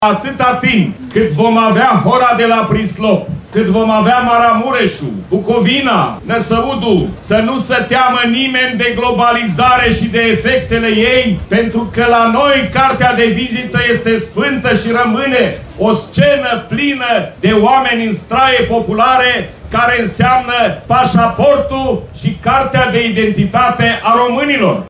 El a declarat ieri – în cadrul evenimentului Hora de la Prislop – că localnicii știu bine să-și conserve obiceiurile din vechime și că, “în esență, tradiția bate modernismul”.